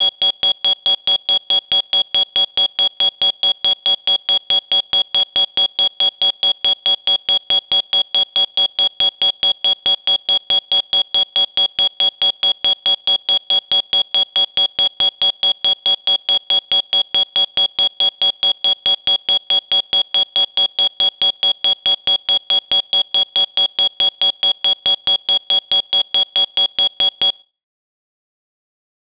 smokedetector.wav